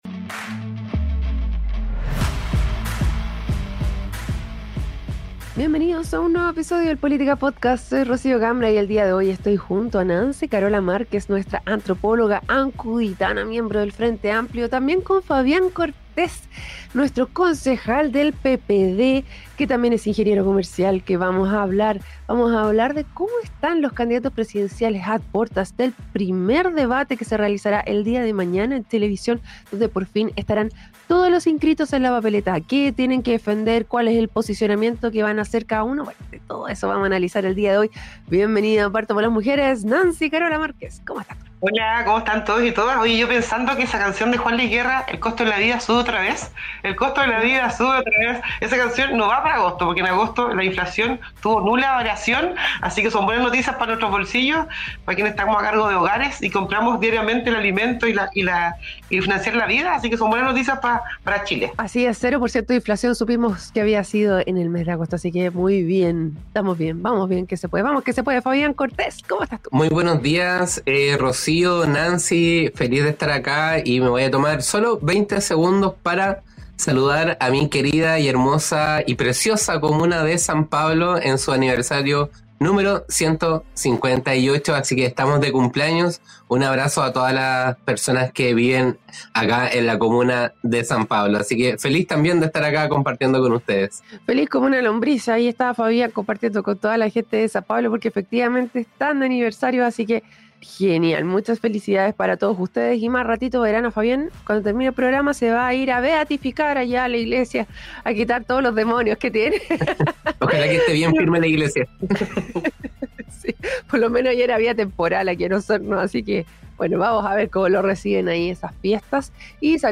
La conversación se centró en las propuestas económicas de cada postulante y el estado actual de sus campañas.